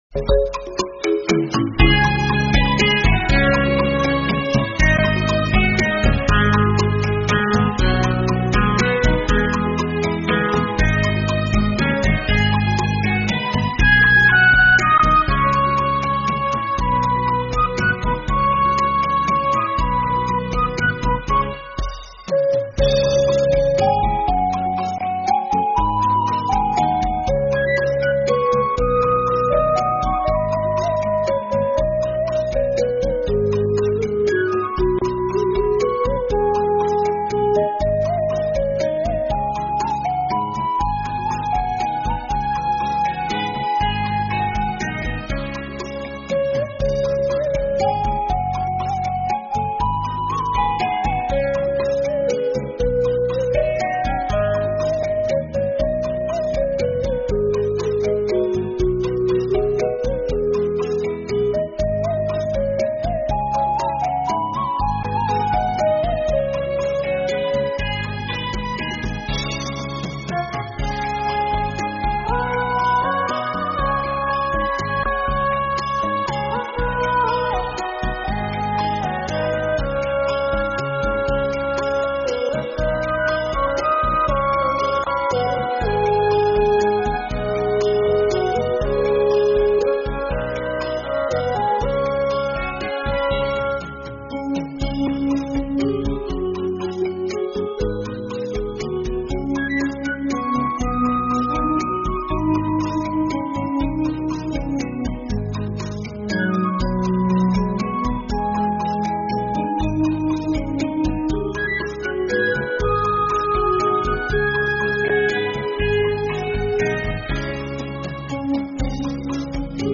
thuyết pháp Tâm Bất Biến Giữa Dòng Đời Vạn Biến
giảng trong đại lễ mừng Phật Đản PL 2557 tại tu viện Trúc Lâm